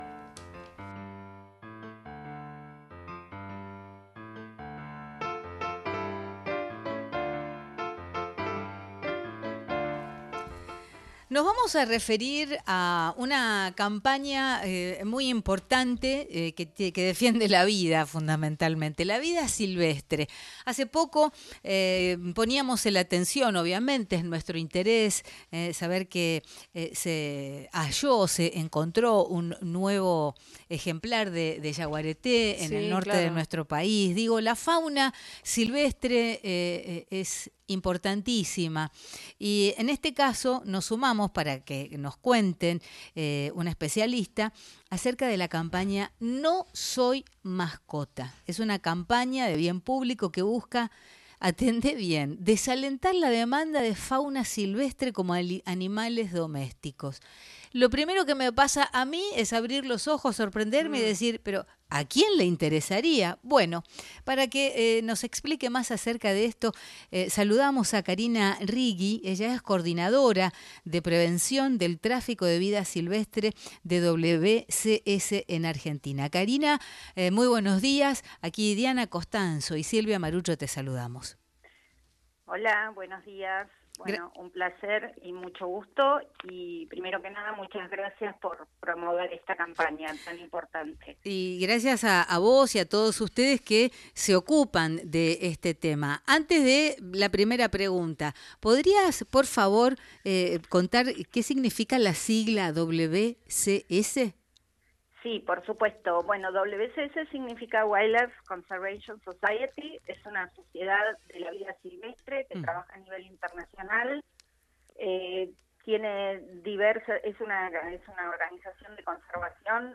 Campaña en contra del tráfico ilegal de animales en Argentina – Radio Nacional